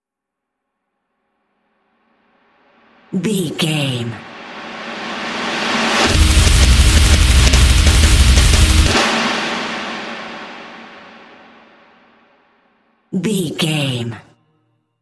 Aeolian/Minor
D
drums
electric guitar
bass guitar
Sports Rock
hard rock
metal
aggressive
energetic
intense
nu metal
alternative metal